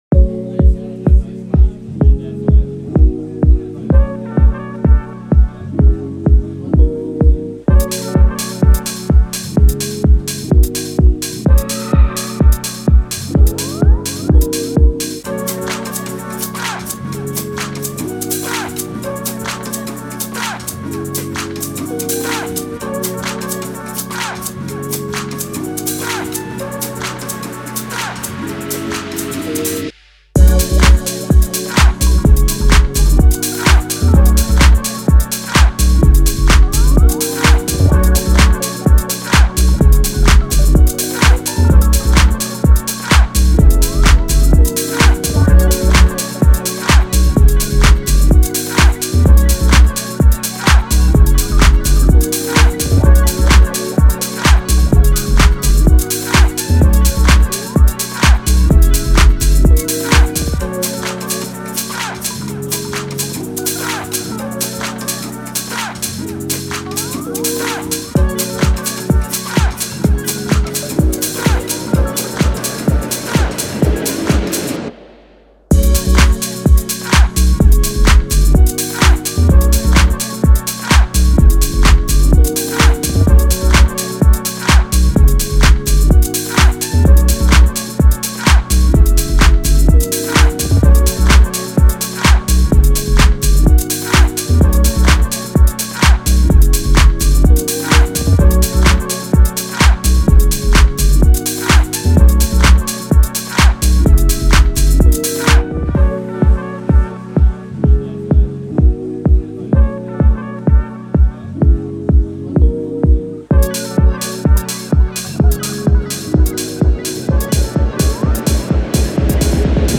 official instrumental
2022 in Hip-Hop Instrumentals